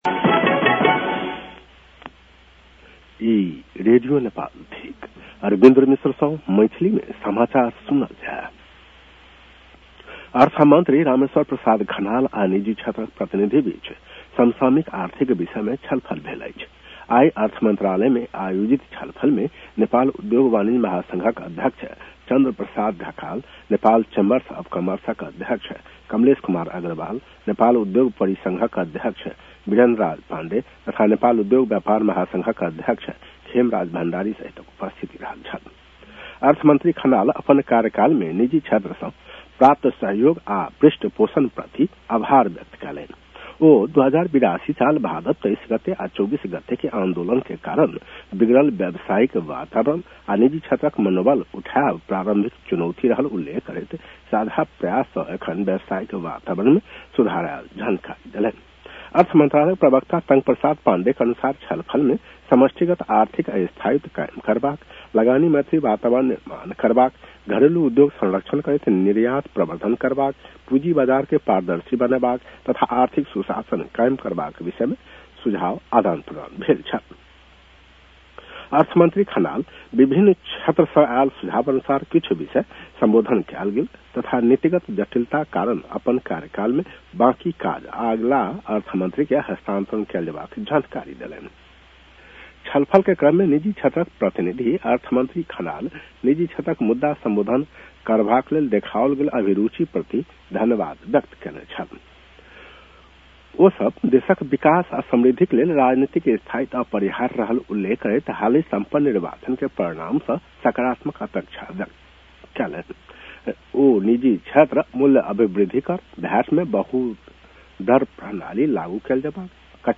मैथिली भाषामा समाचार : २८ फागुन , २०८२
Maithali-news-11-28.mp3